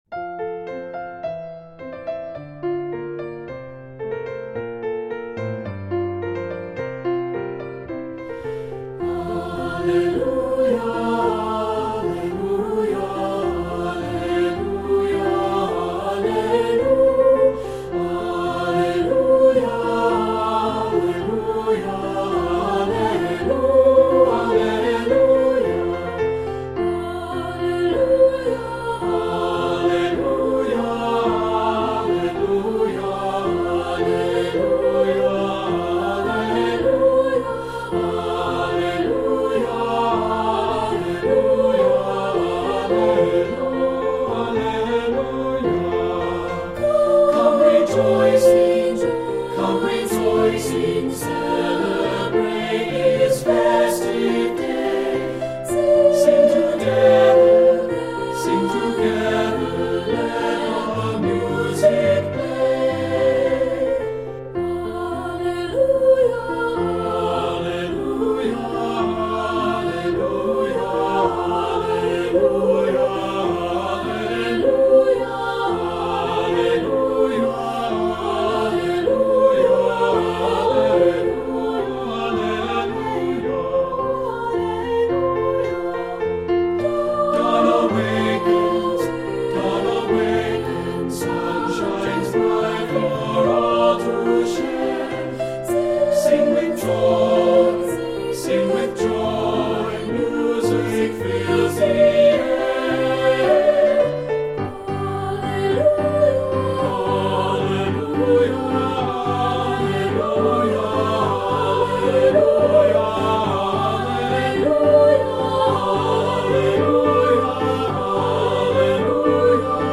Voicing: Three-part